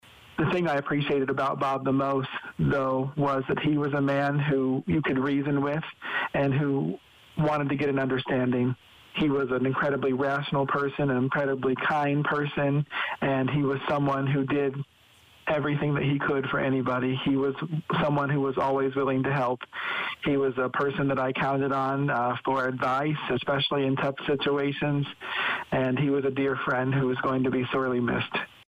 Danville’s Mayor Rickey Williams, Jr spoke with CIMG on Friday about his memories of Danville Alderman and Former Vice-Mayor Bob Iverson, who passed away in Peoria on Thursday, May 22nd.